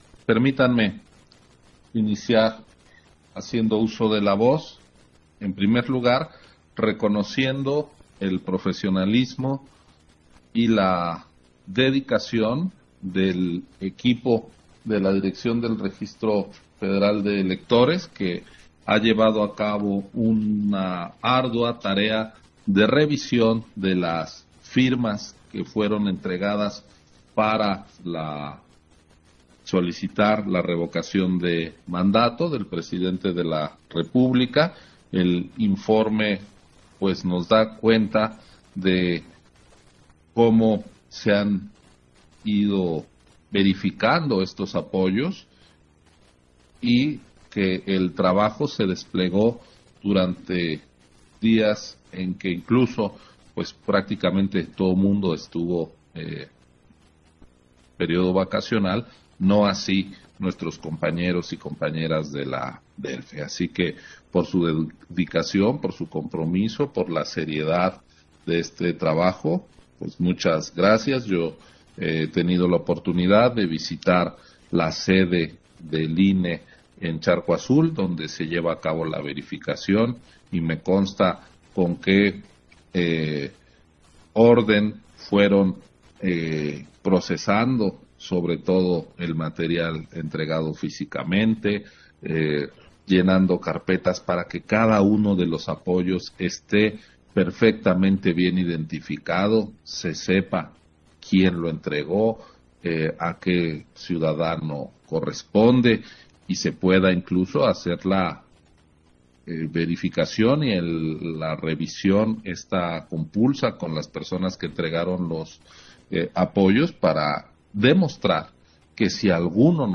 Intervención de Ciro Murayama, en la Comisión del Registro Federal de Electores, relativo al informe preliminar del proceso de verificación de firmas para la Revocación de Mandato